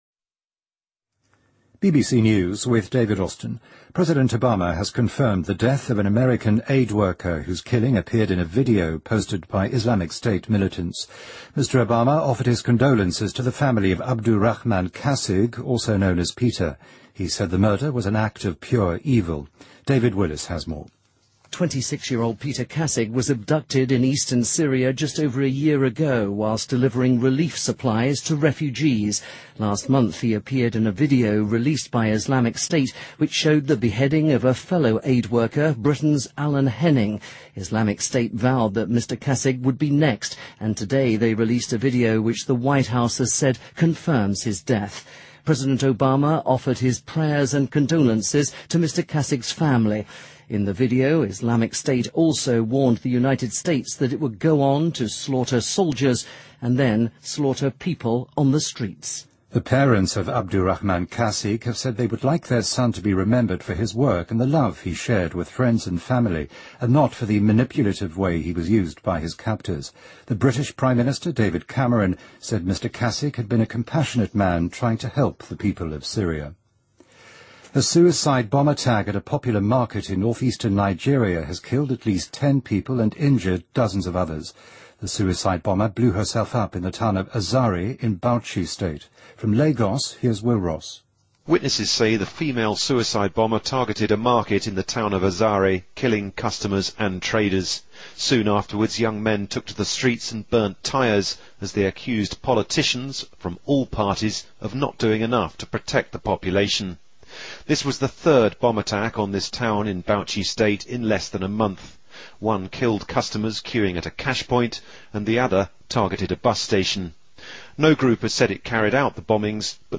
您的位置：BBC > BBC在线收听 > 11月新闻 > 最新BBC新闻